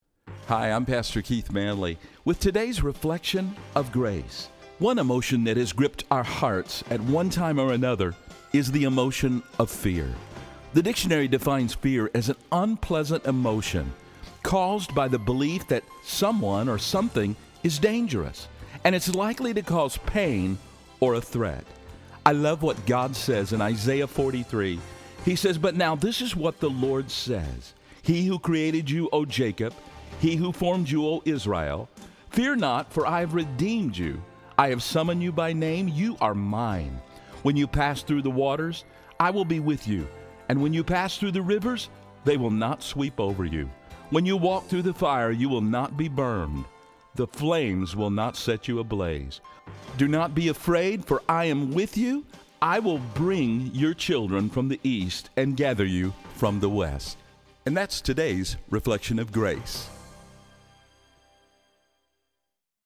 These spots air locally on 93.3 FM and on the Wilkins Radio Network heard in 27 Radio Stations around the country.